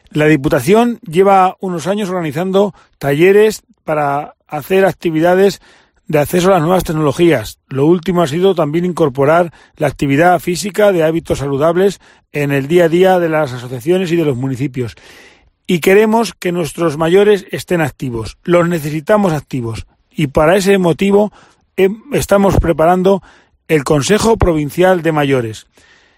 Así lo ha anunciado Benjamín Prieto durante la inauguración este martes en Iniesta del I Encuentro Provincial de Actividad Física para Mayores